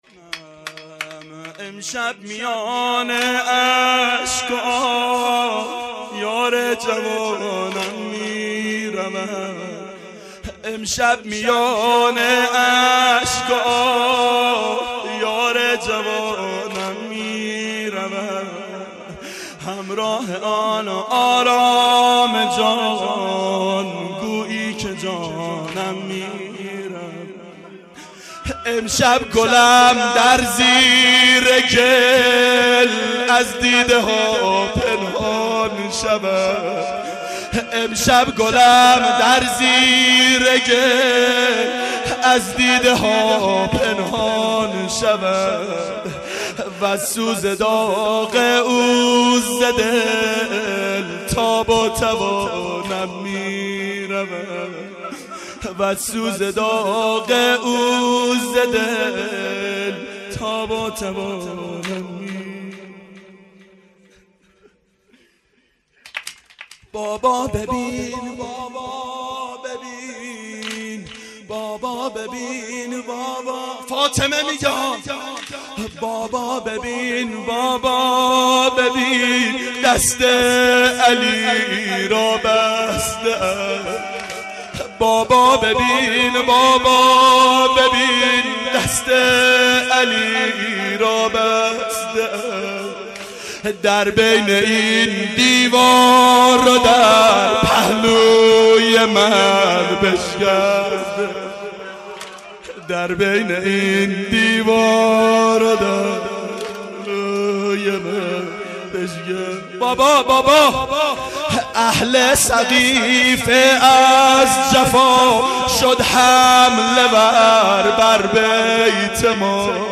• ظهر شهادت حضرت زهرا سلام الله علیها1392 هیئت شیفتگان حضرت رقیه س